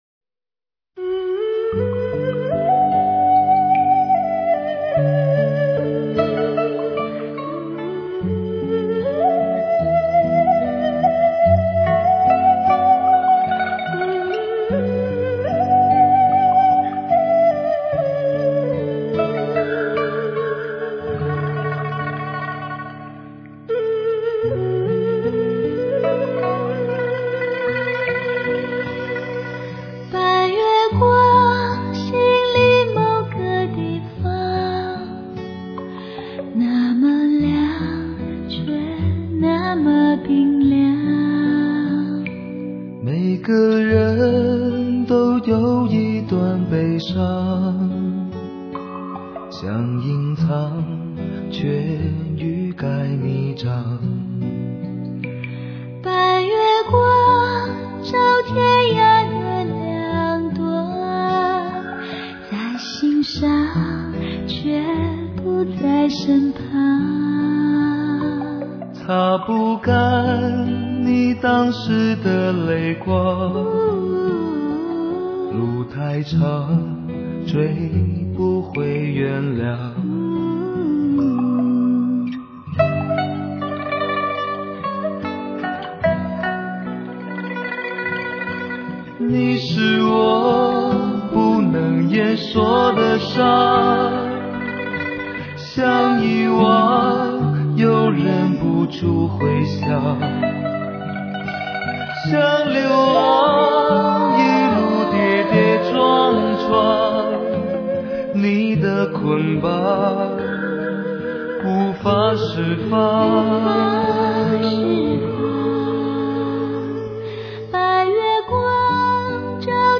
感性而略带哽咽沙哑的男声，甜美而清新圆润的女声
录音方面饱满清晰而又细腻自然，层次感极强，为专辑增色不少。
少数几首使用的电声乐器，也只是强调了一种释放的愉悦心情。
东方乐器作主线，守望着古典空间，生命让人肃穆，真爱四季流淌。
经典歌曲重唱专辑。